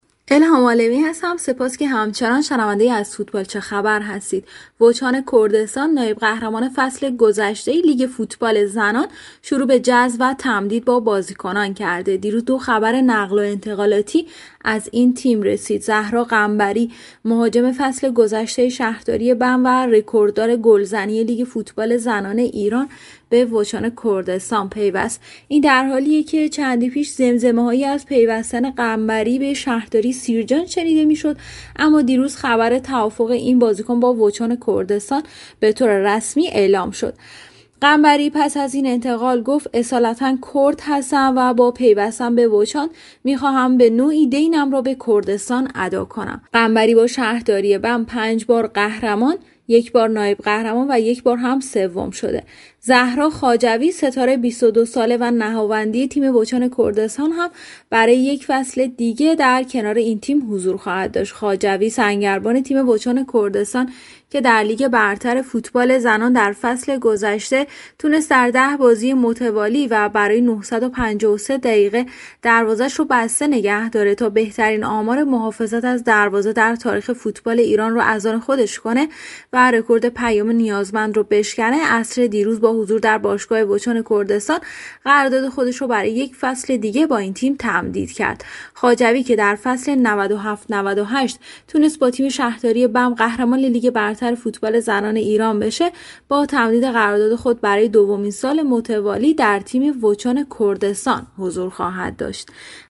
برنامه زنده "از فوتبال چه خبر؟"